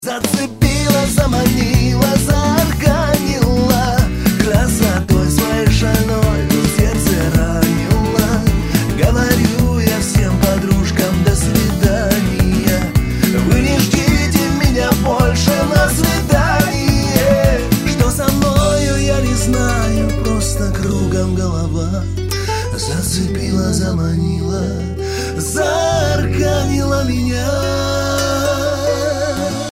Шансон, Авторская и Военная песня